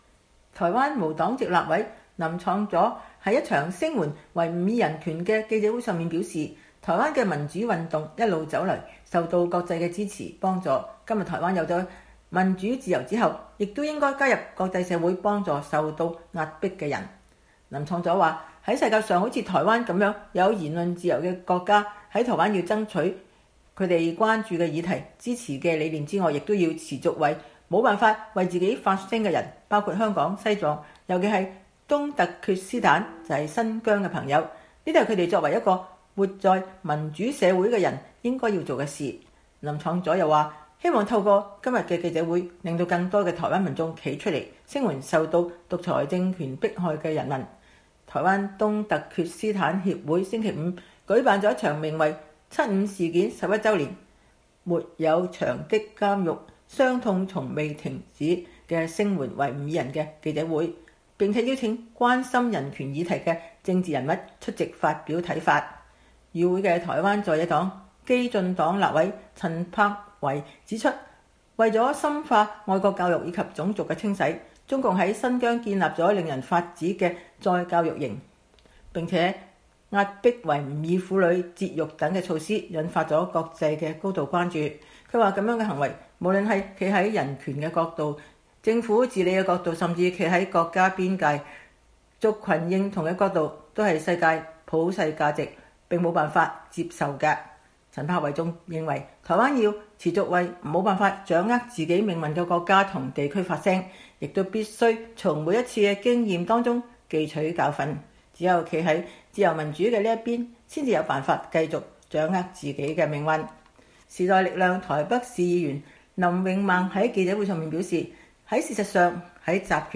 新疆七五事件11週年前夕，台灣年輕世代政治人物在一場記者會上聲援維吾爾人權，並希望有更多的台灣民眾站出來聲援受到獨裁政權迫害的人民。
台灣無黨籍立委林昶佐在一場聲援維吾爾人權的記者會上表示，台灣的民主運動一路走來，受到國際的支持、幫助，今天台灣有了民主自由之後，也應該加入國際社會幫助受到壓迫的人。
時代力量台北市議員林穎孟在記者會上表示，事實上，在集權國家當中，少數民族女性受到的迫害更為嚴重。